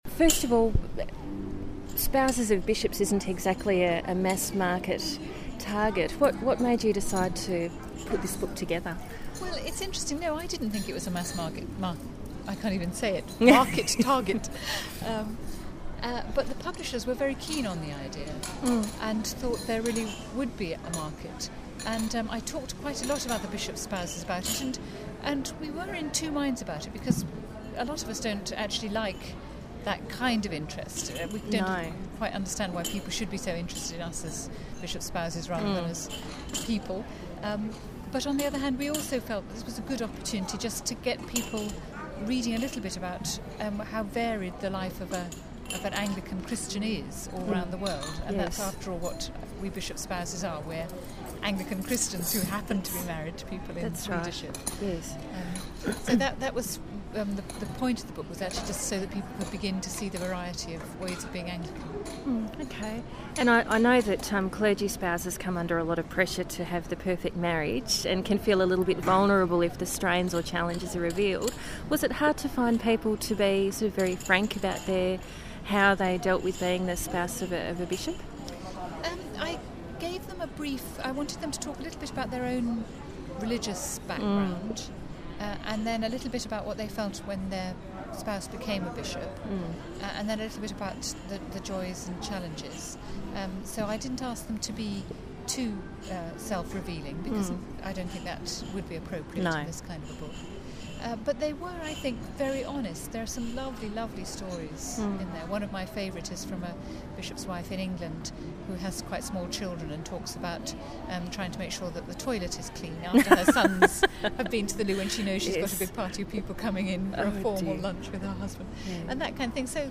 LC2008 Interview